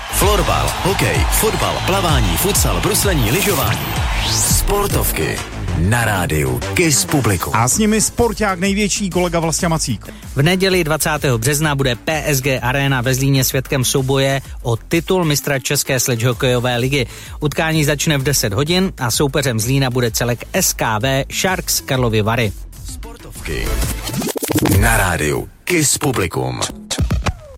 Upoutávka na superfinále ČSHL 2015/2016